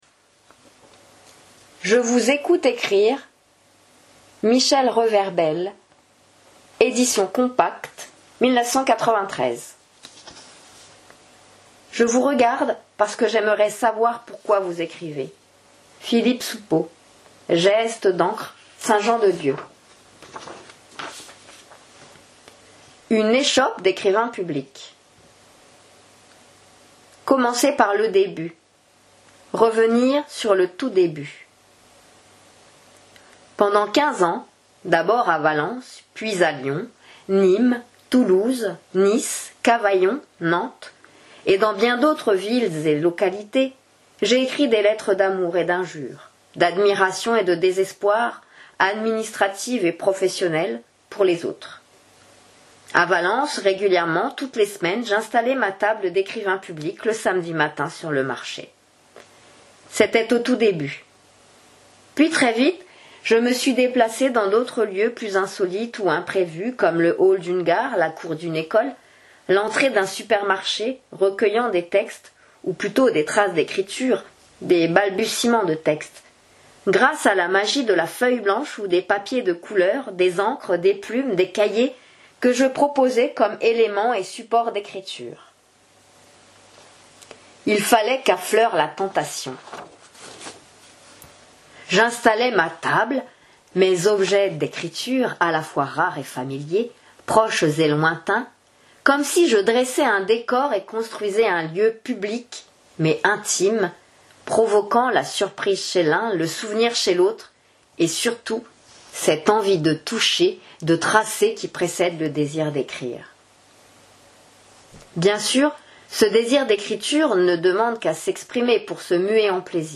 Extrait lu à voix haute [p.9 - 15] [12'21]